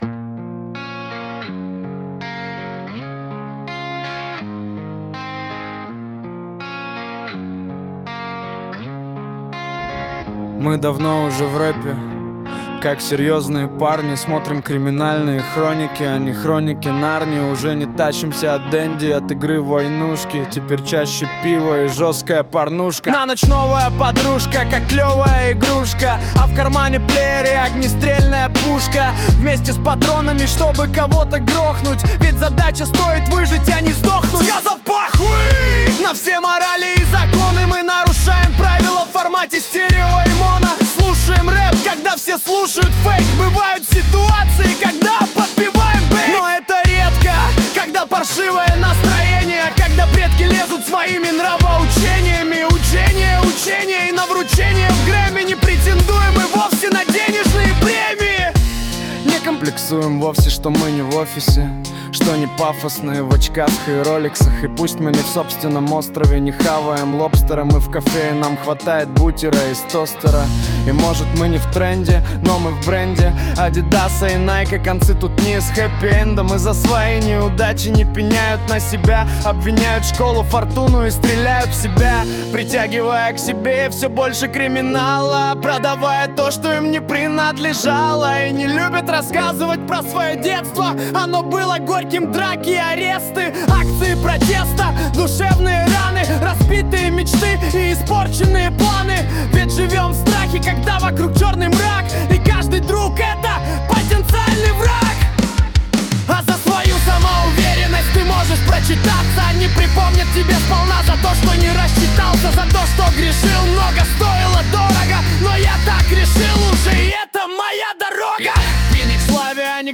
рэп_рок_Remix2
rjep_rok_Remix2.mp3